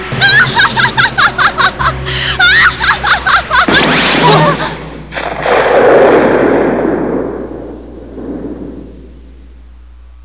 Eris' laugh is really starting to get irritating, so "someone" shuts her up(10.24 sec, 112K)